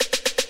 Snares
JJSnares (32).wav